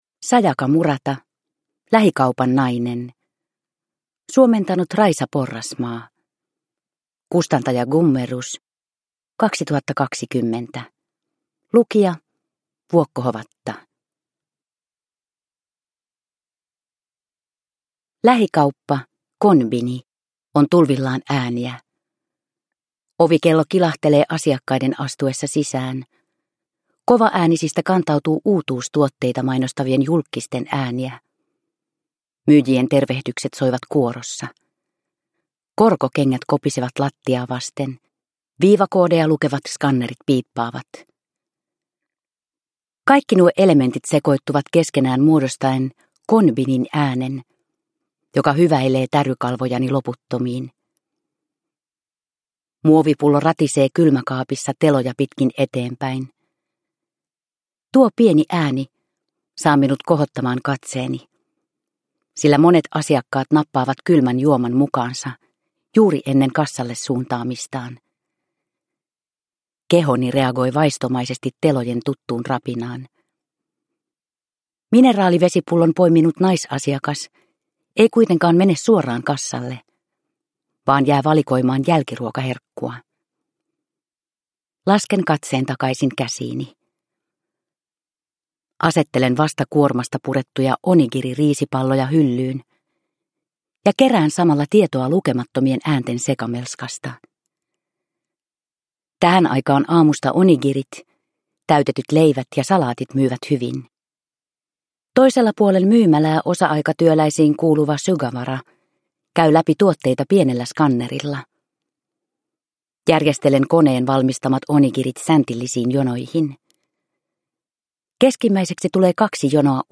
Lähikaupan nainen – Ljudbok – Laddas ner
Uppläsare: Vuokko Hovatta